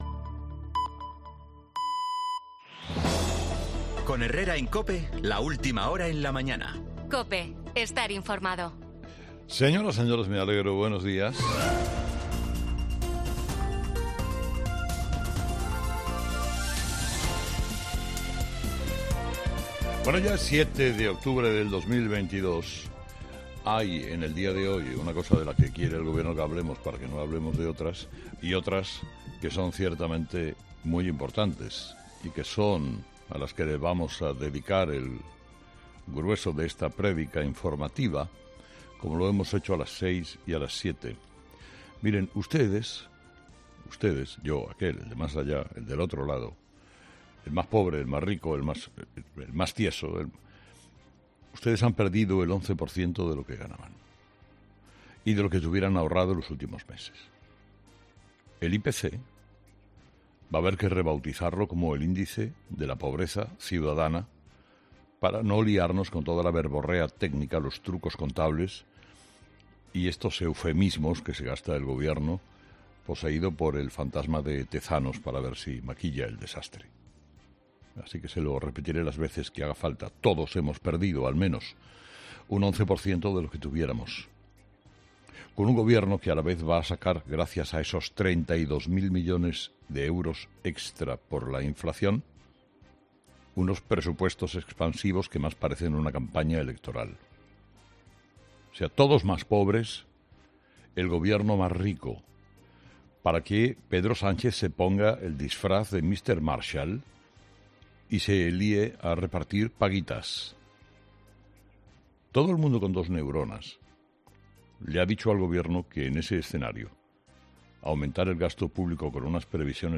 La subida del sueldo del Gobierno o el "anzuelo" del Colegio Mayor de Madrid, en el monólogo de Carlos Herrera de este viernes 7 de octubre de 2022